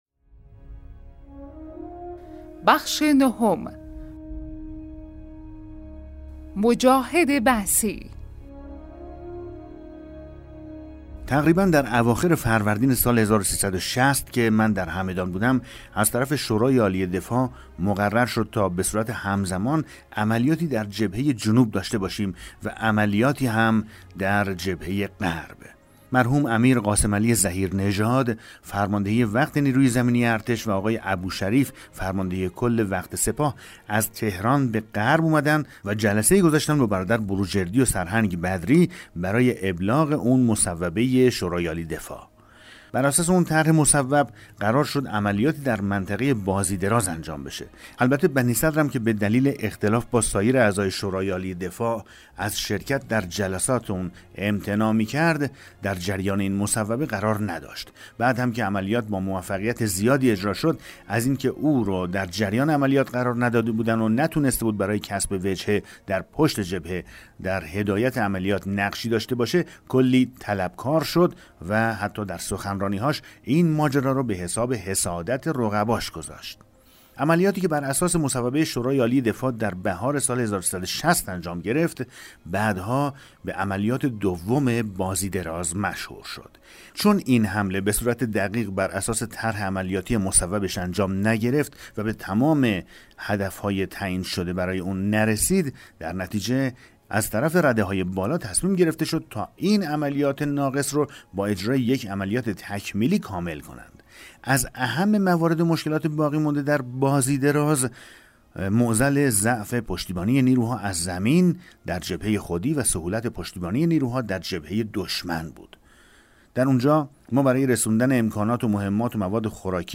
کتاب صوتی پیغام ماهی ها، سرگذشت جنگ‌های نامتقارن حاج حسین همدانی /قسمت 9
بخش‌هایی از این کتاب ارزشمند را در همدان صداگذاری کرده اند.